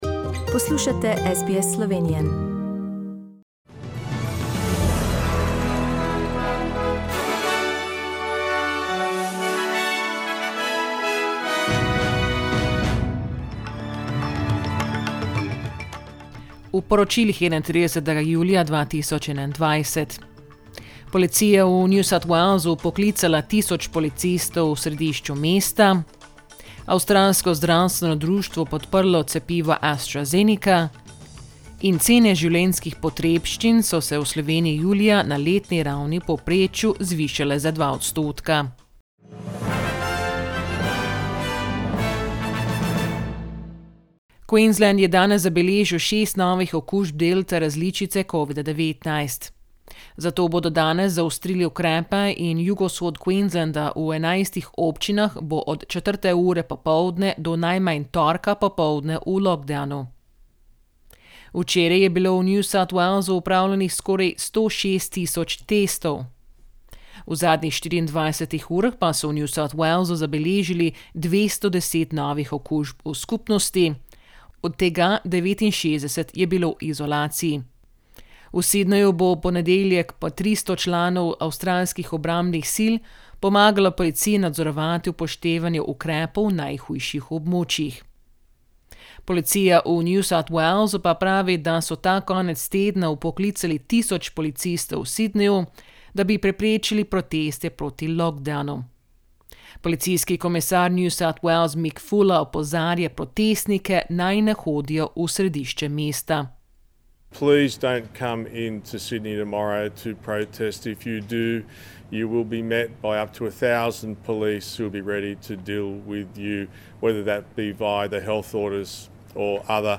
SBS News in Slovenian - 31st July, 2021